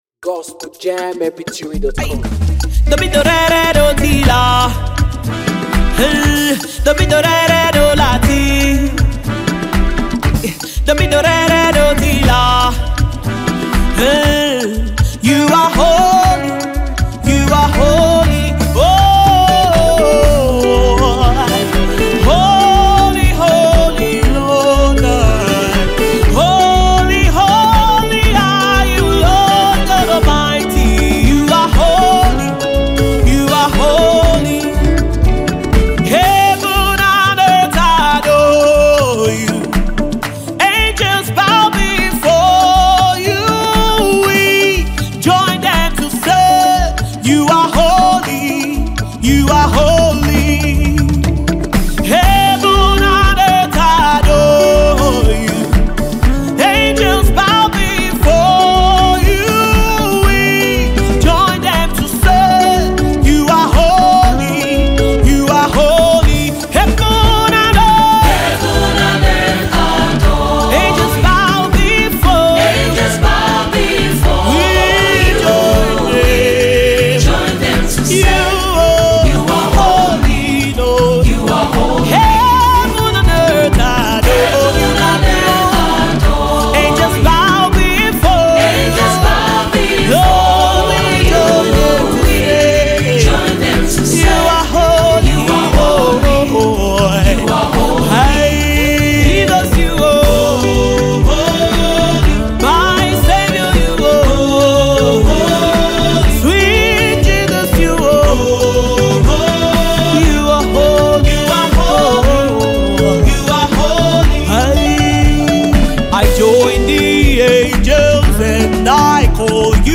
African praiseAfro beatmusic